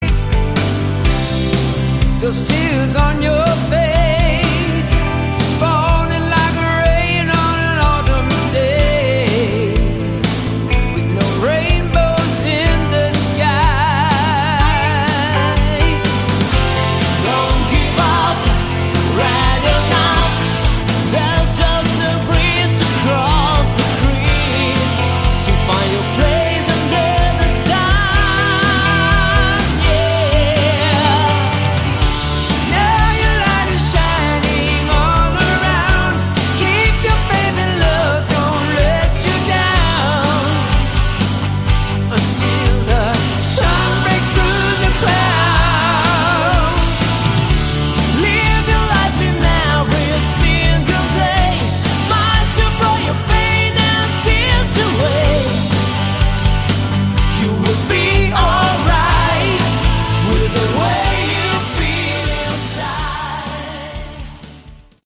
Category: AOR
bass
vocals, keyboards
drums, percussion
backing vocals
guitar